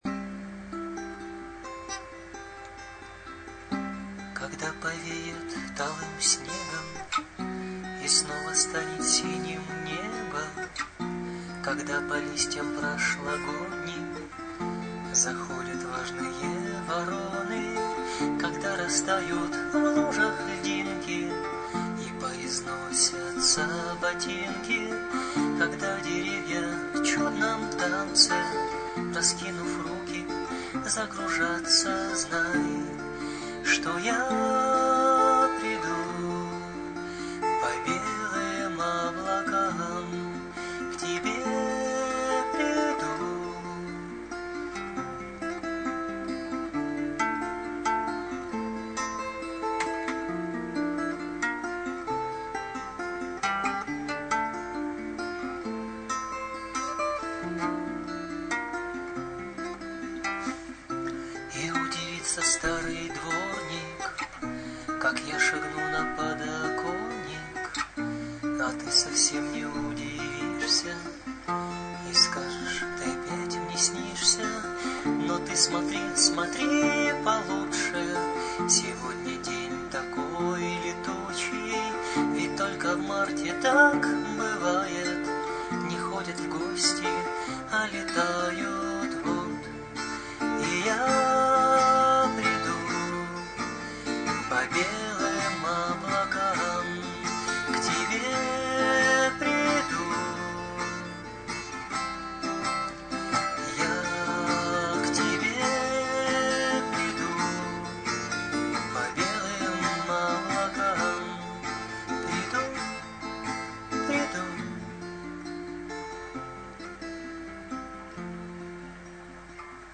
Песня весенняя и, фактически, немножко продолжает сказку... ;)